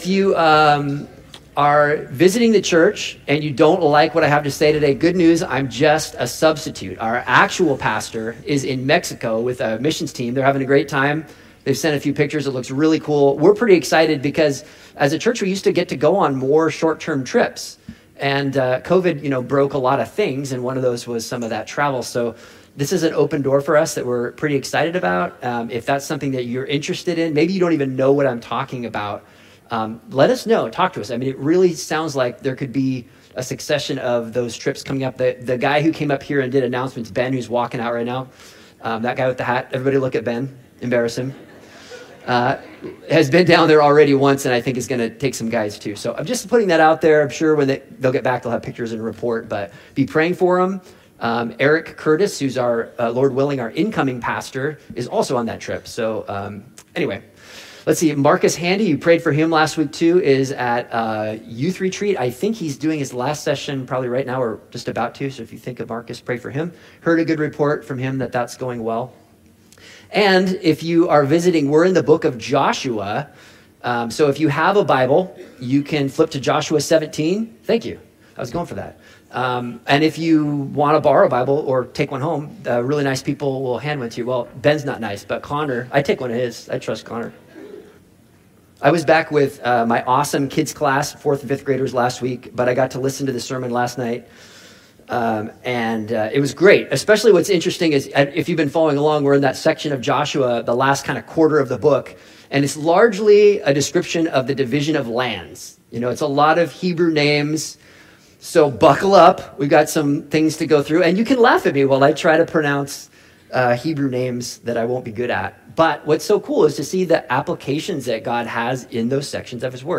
Sermons | Coastline Christian Fellowship